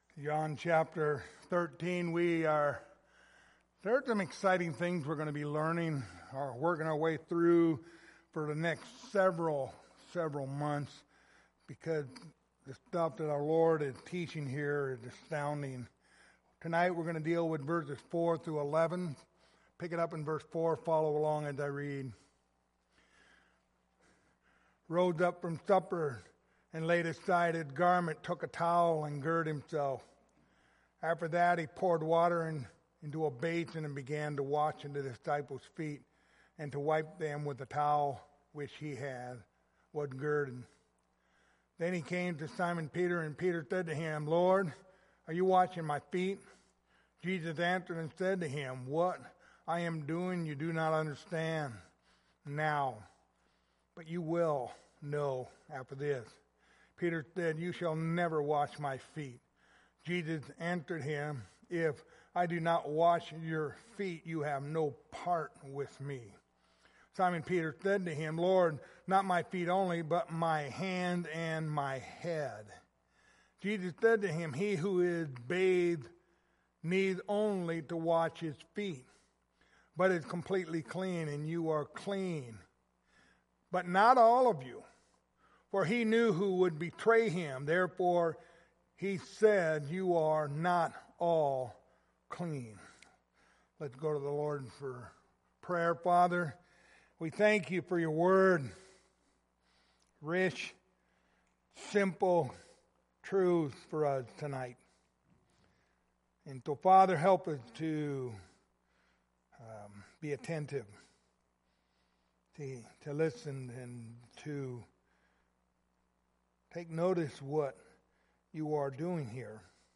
Passage: John 13:4-11 Service Type: Wednesday Evening